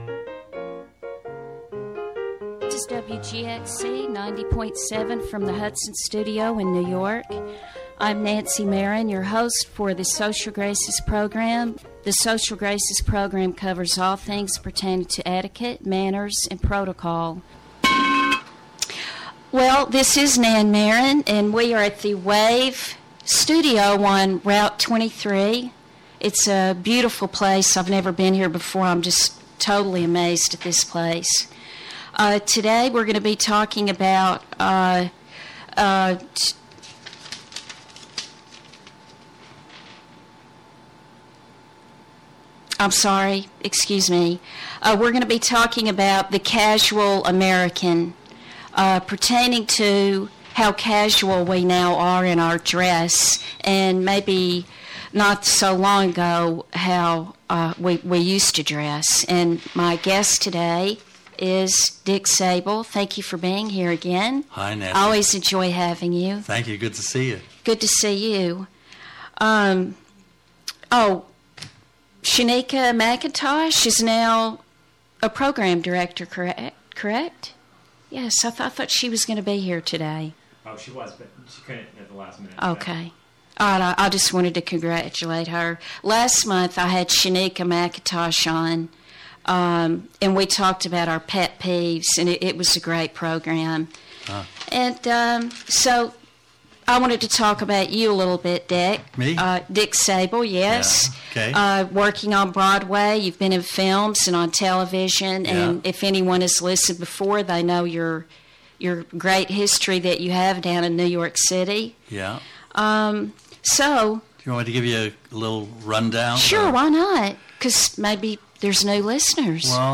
Recorded live at Wave Farm in Acra